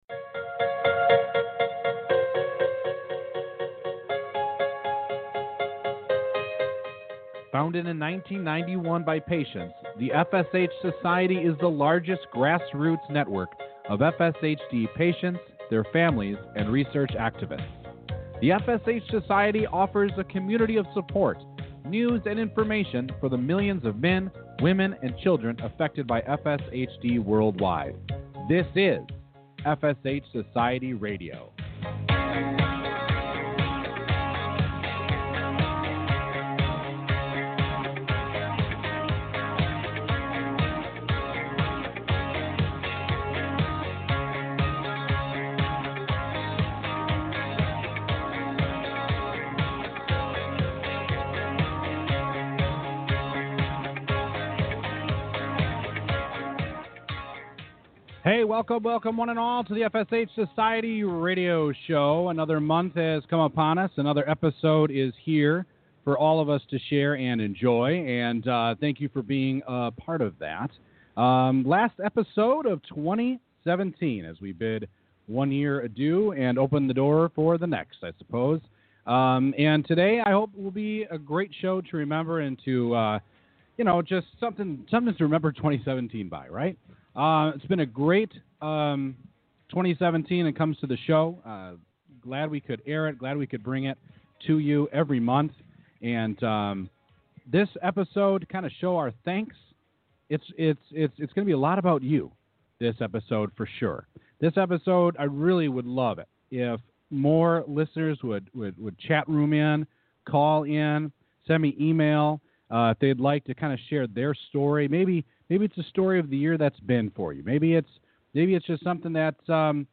On this episode, the featured guests are you the listener!